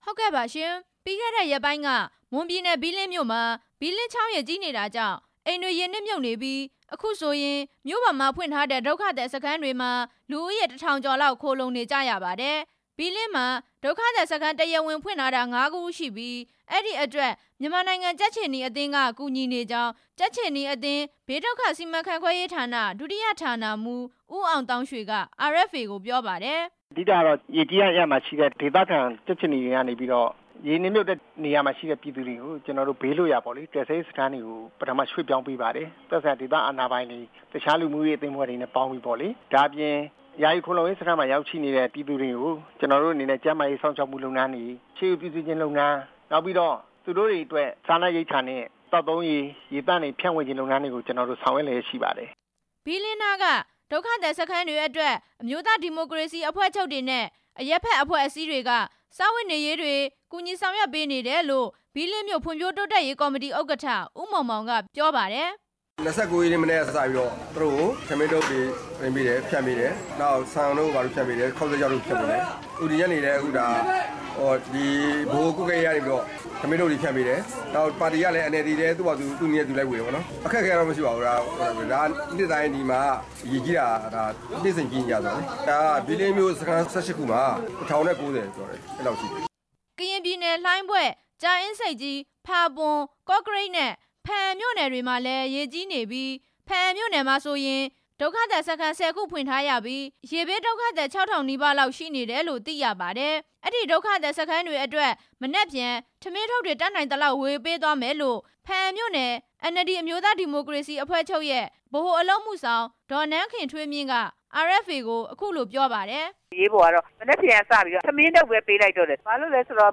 ရေဘေး တင်ပြချက်